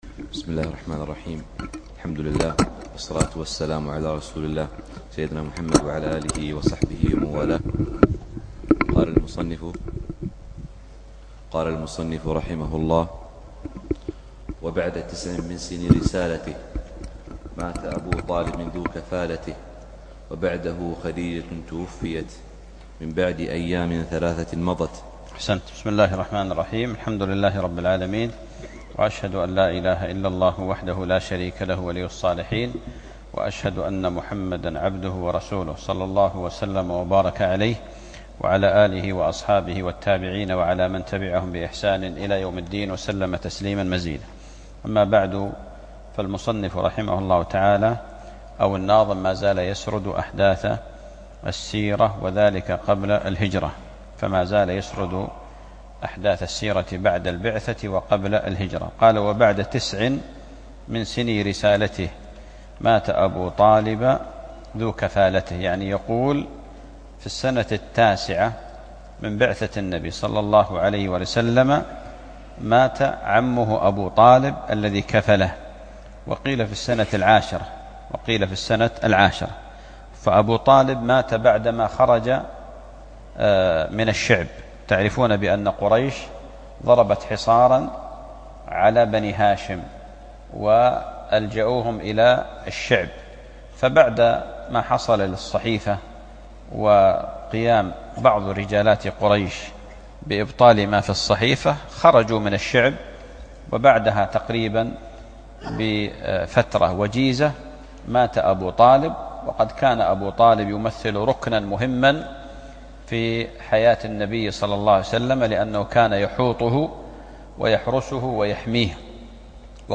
الدرس الرابع